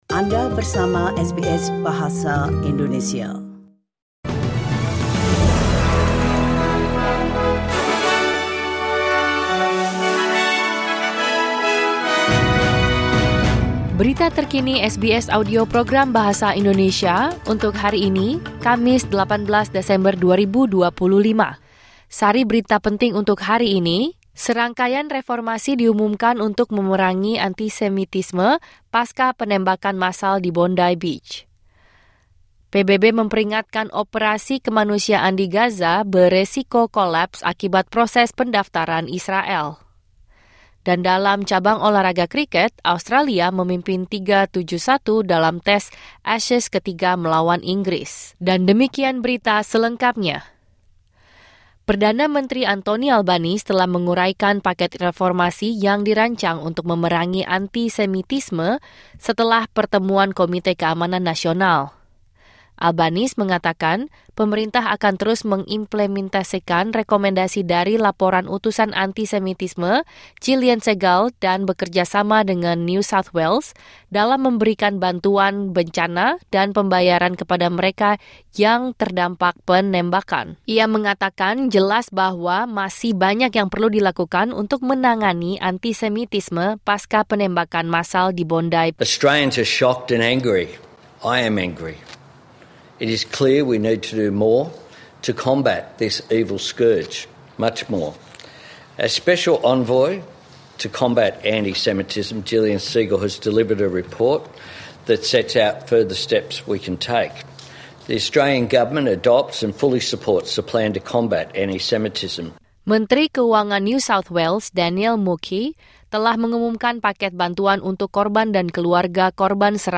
Berita Terkini SBS Audio Program Bahasa Indonesia – Kamis 18 Desember 2025